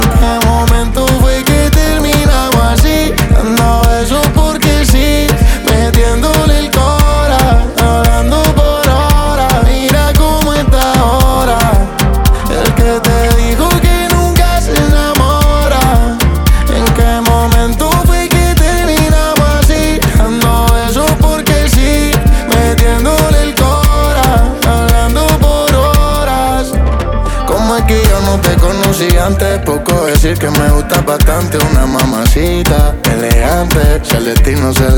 Pop Latino, Latin, African, Afro-Beat